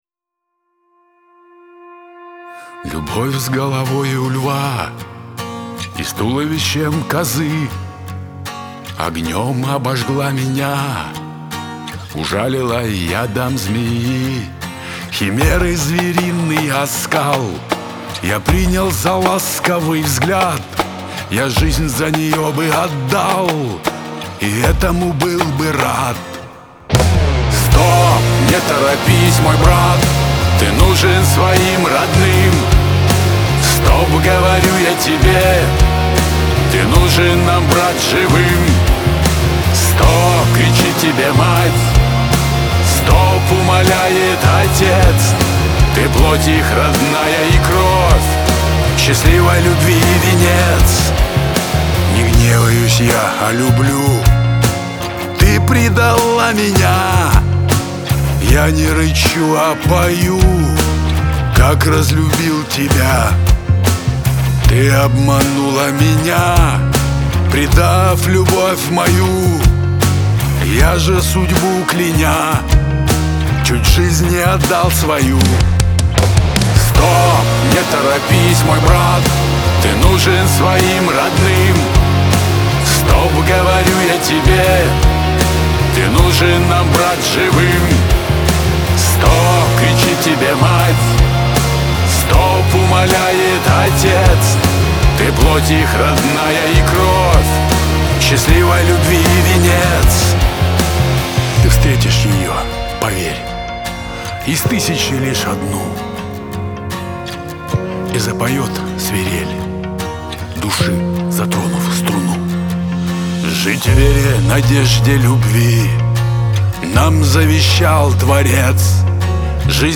грусть , Шансон
Лирика